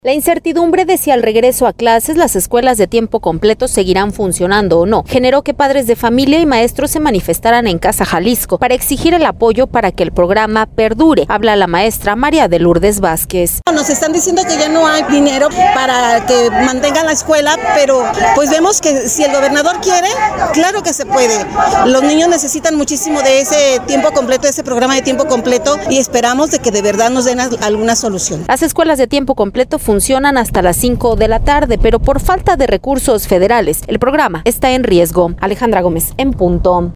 La incertidumbre de si al regreso a clases, las escuelas de tiempo completo seguirán funcionando o no, generó que  padres de familia y maestros se manifestaran en Casa Jalisco, para exigir el apoyo para que este programa perdure. Habla la maestra